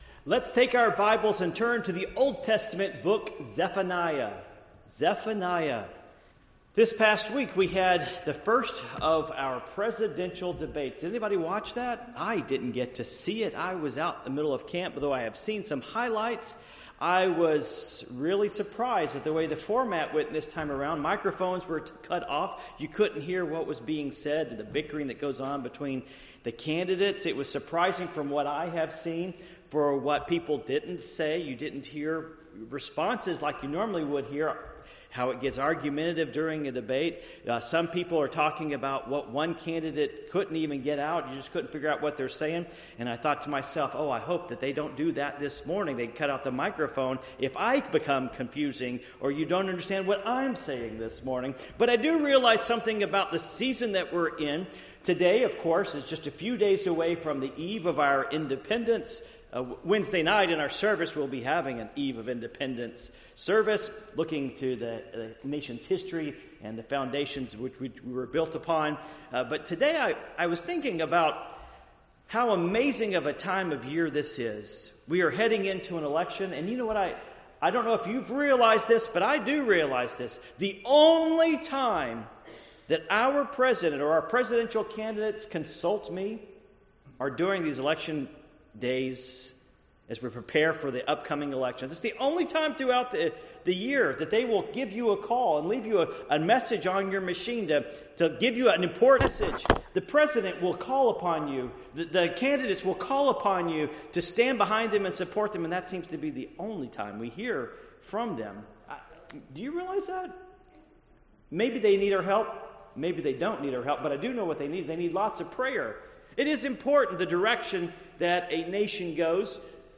Passage: Zephaniah 3:14-17 Service Type: Morning Worship